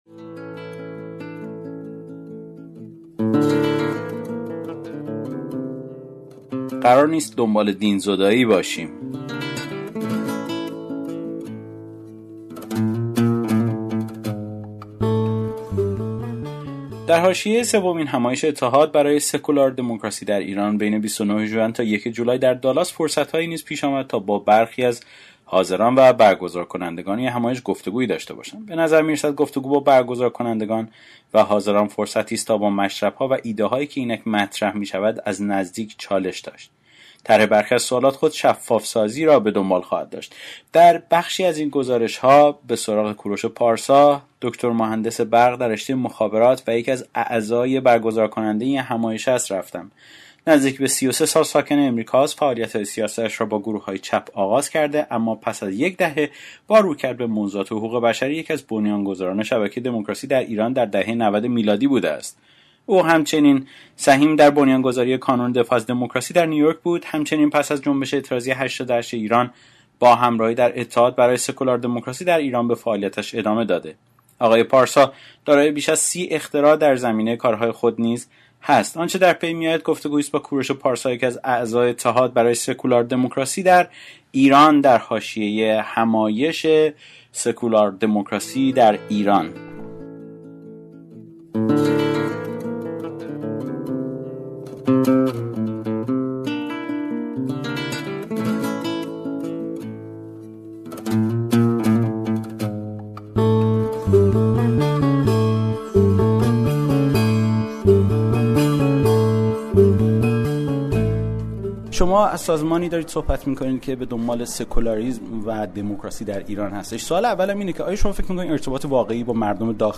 گفت‌و‌گویی